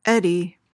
PRONUNCIATION: (ED-ee) MEANING: noun:1.